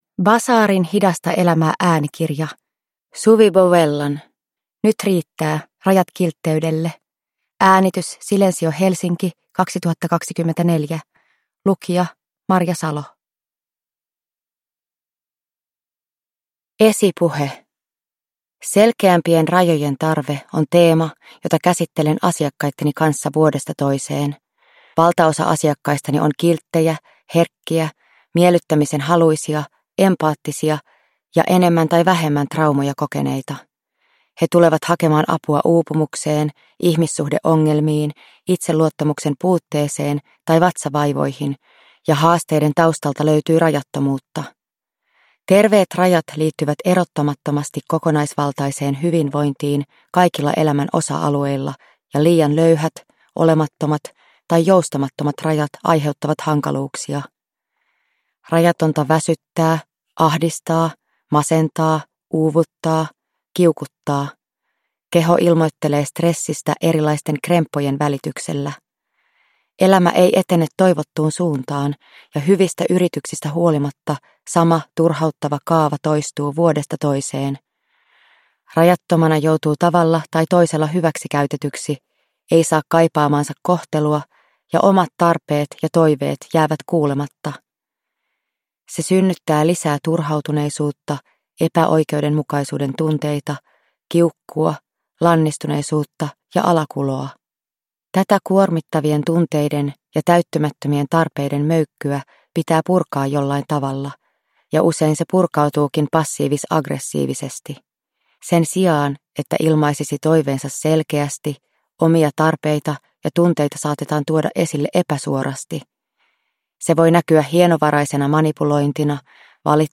Nyt riittää – rajat kiltteydelle – Ljudbok